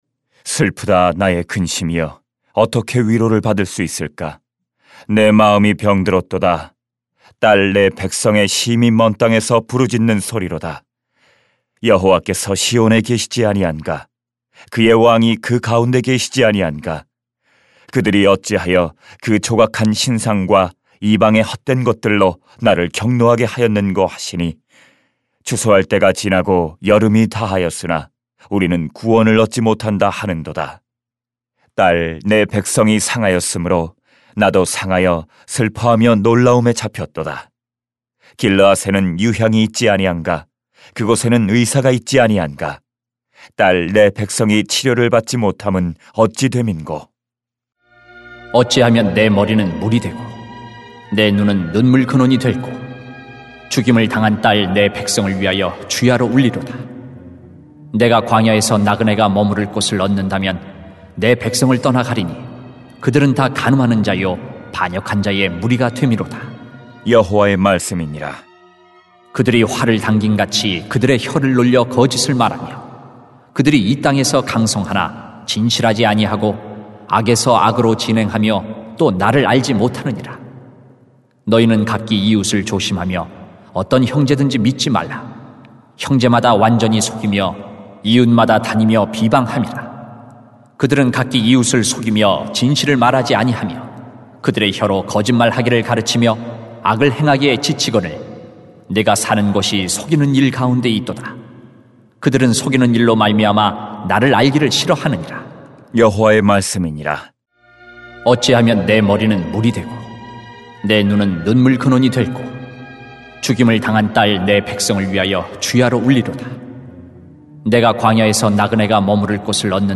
[렘 8:18-9:6] 세상을 위한 눈물이 필요합니다 > 새벽기도회 | 전주제자교회